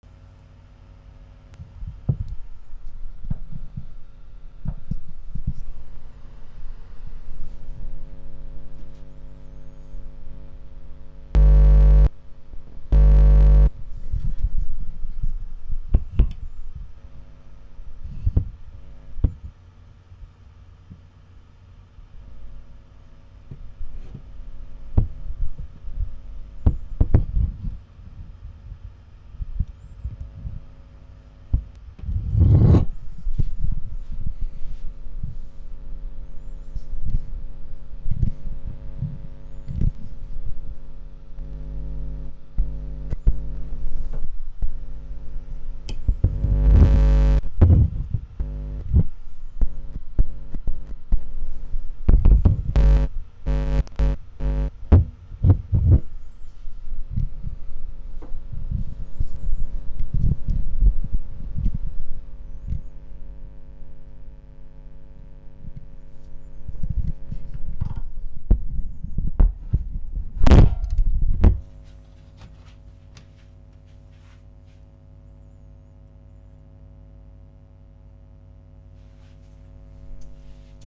Шум на записи конденсаторного микрофона
Конденсаторный микрофон пишет сигнал с низкочастотным шумом.
Подключен в интерфейс со встроенным предусилителем.
В первые секунды шум почти отсутствует. Затем я слегка поворачиваю микрофон и шум появляется. При повороте микрофона и кабеля шум может стать как тише, так и громче. Громкий гул появляется, когда я прикасаюсь к металлической сетке головы микрофона.